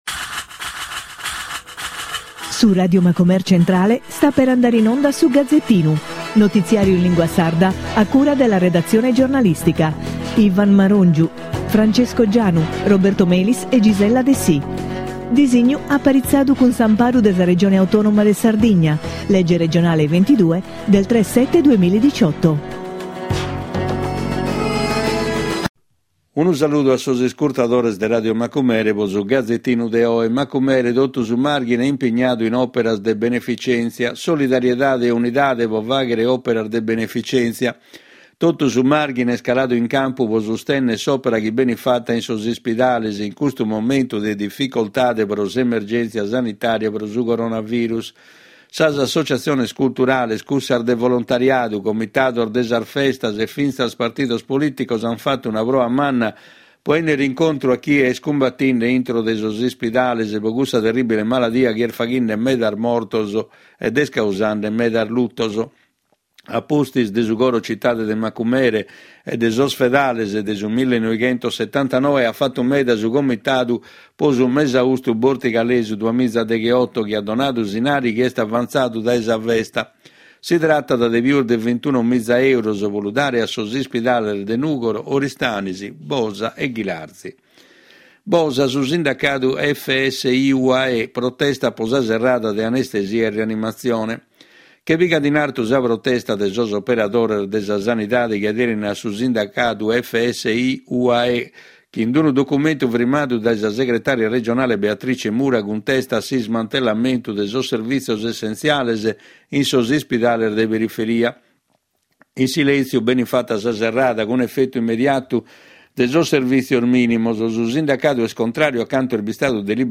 Notiziario in lingua sarda con la consulenza di esperti. Le principali notizie nazionali e nel dettaglio quelle regionali con particolare riferimento all’attività socio economica e culturale della nostra isola con un occhio particolare al mondo dei giovani.